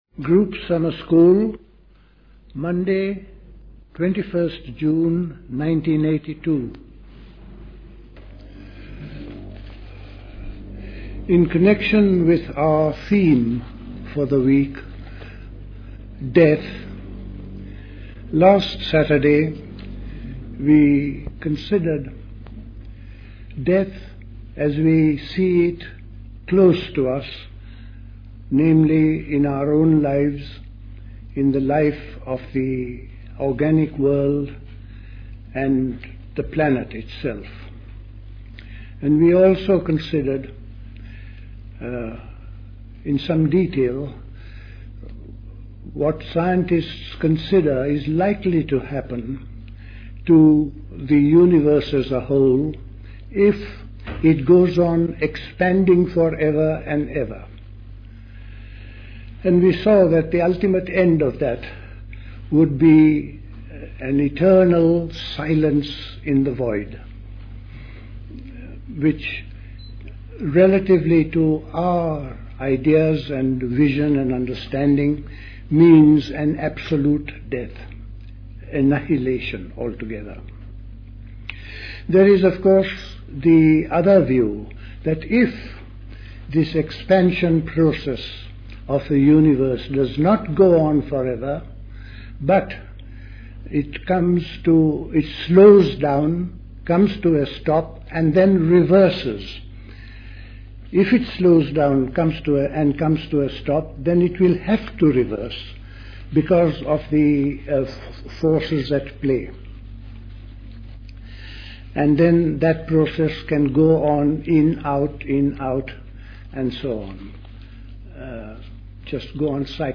The Cenacle Summer School Talks